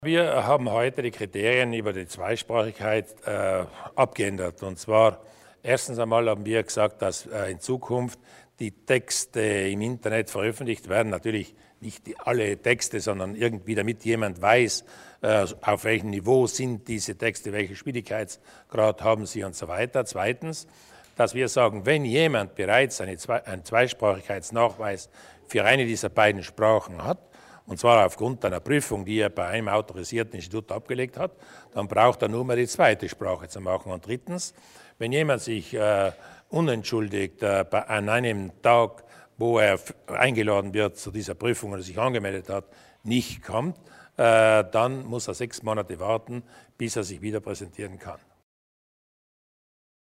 Landeshauptmann Durnwalder zu den Neuerungen bei der Zweisprachigkeitsprüfung